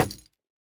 Minecraft Version Minecraft Version latest Latest Release | Latest Snapshot latest / assets / minecraft / sounds / block / bamboo_wood_hanging_sign / break4.ogg Compare With Compare With Latest Release | Latest Snapshot